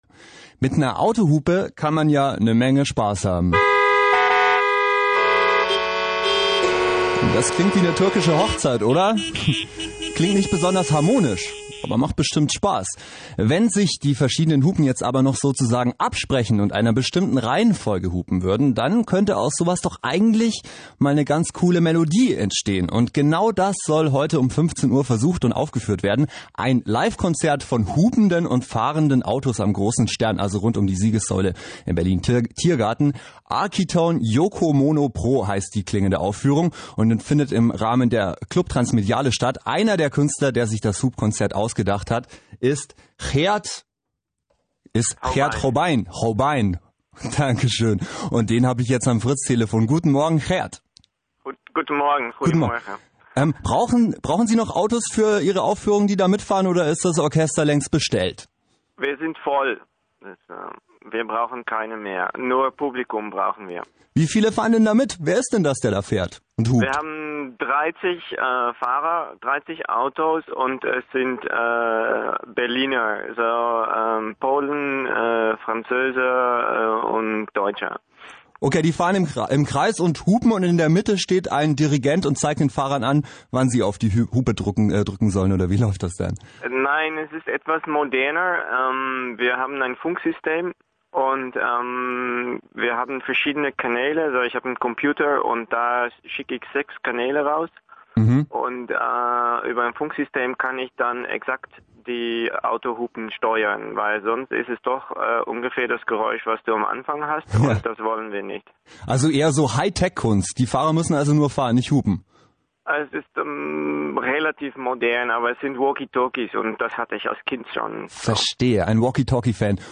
Yokomono-Pro features 3 groups of cars – 30 vehicles in total – playing synched sound-patterns and driving a carefully choreographed route around  the siegessäule for a period of approx. 30 minutes.
Berlin radio RBB on yokomono-pro ! during and after the show , with audience questions
before the show, with interview